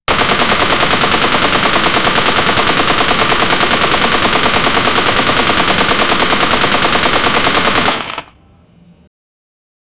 Machine gun firing sound
machine-gun-firing-sound--42uxzg7p.wav